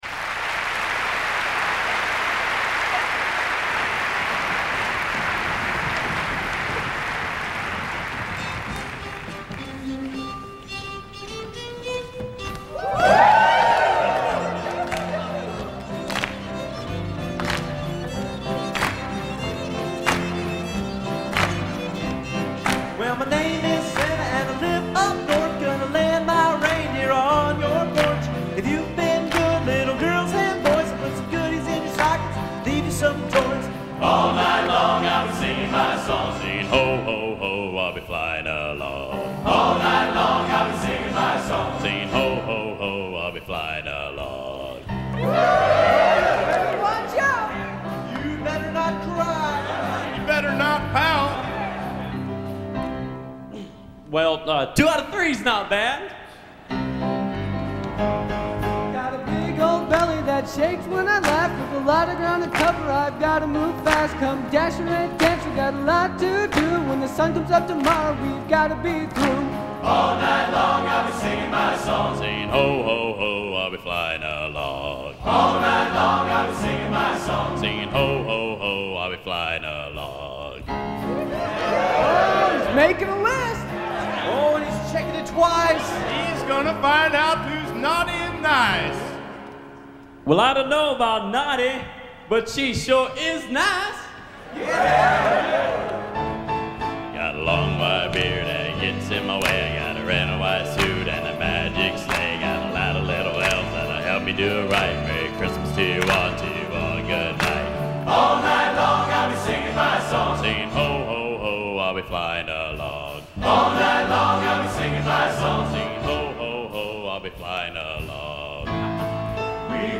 Location: West Lafayette, Indiana
Genre: | Type: Christmas Show |